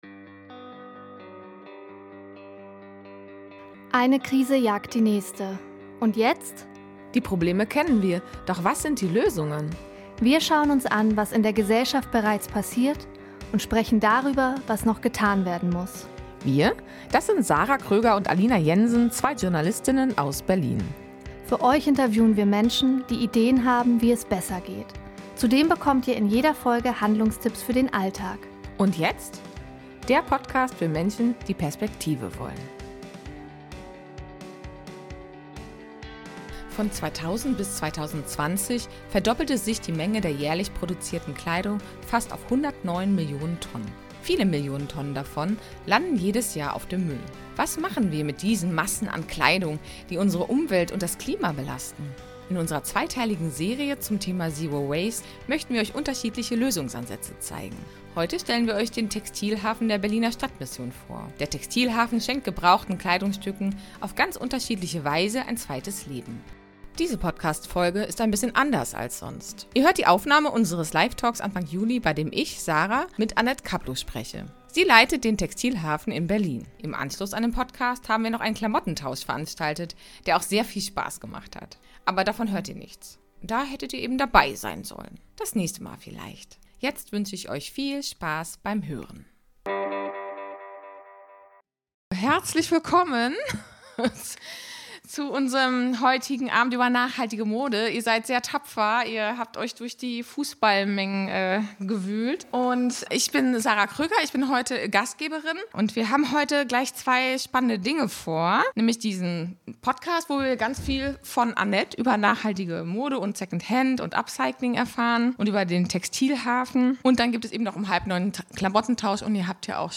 Live-Podcast_fertig.mp3